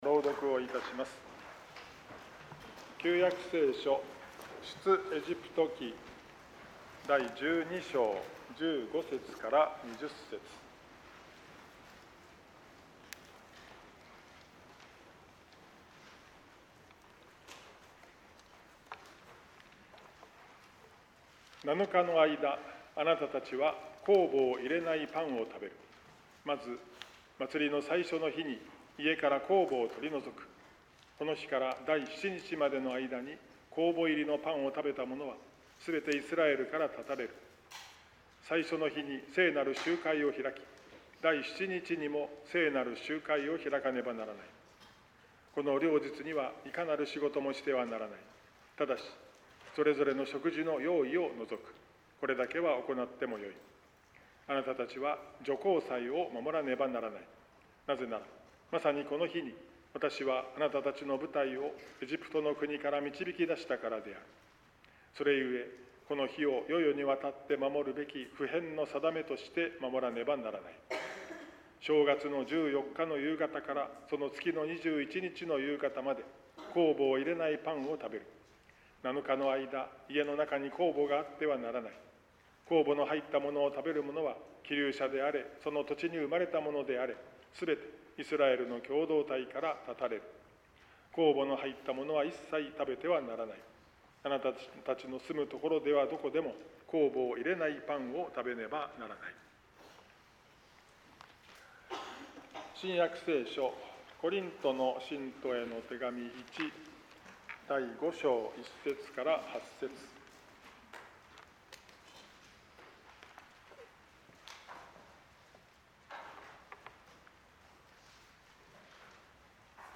説教題